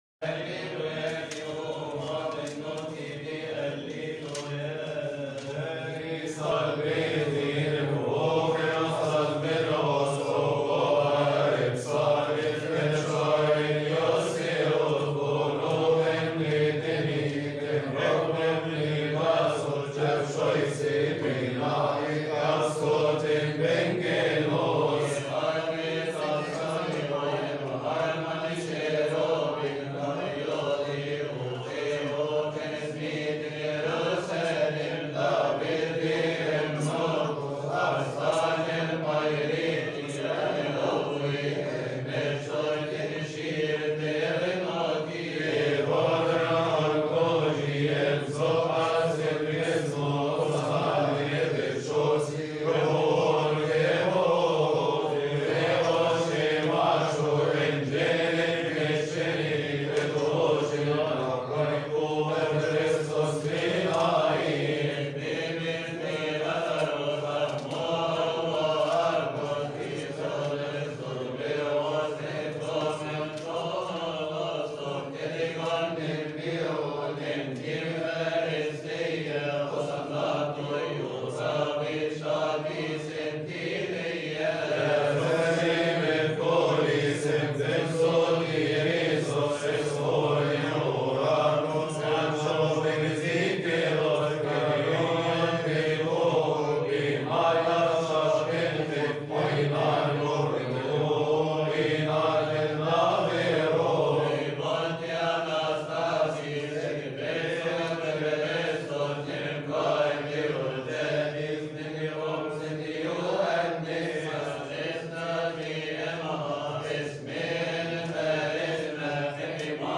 • المصدر : رهبان الدير المحرق
ابصالية ادام على التذاكية فى احد الشعانين لرهبان الدير المحرق، قبطي.
ابصالية-ادام-على-التذاكية-تسبحة-نصف-الليل-أحد-الشعانين-رهبان-الدير-المحرق-1.mp3